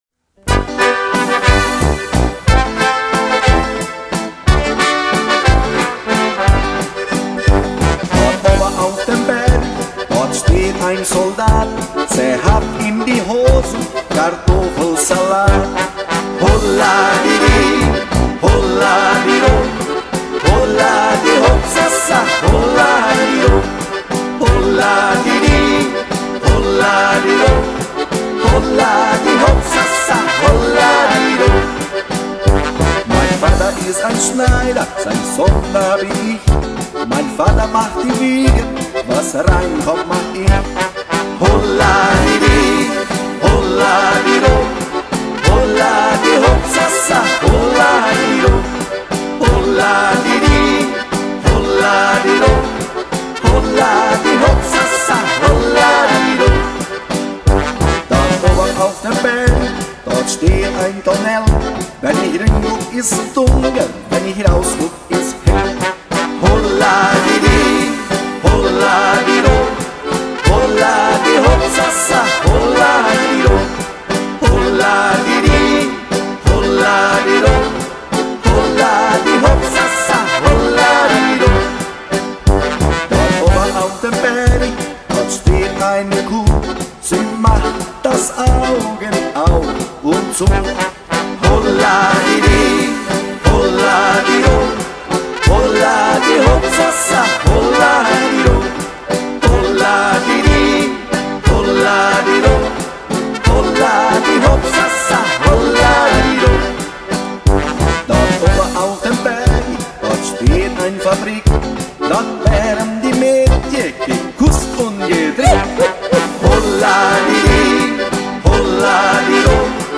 Folclore Alemão.